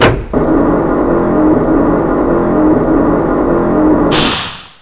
notification (text and et cetera):